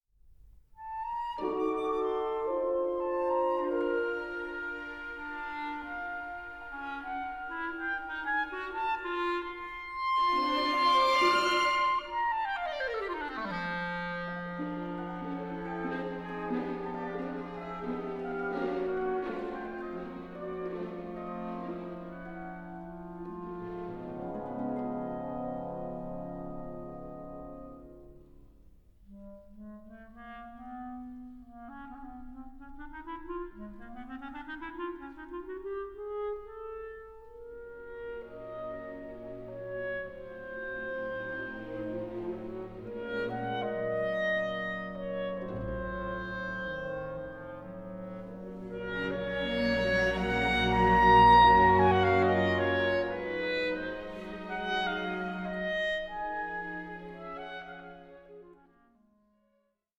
Belgian clarinettist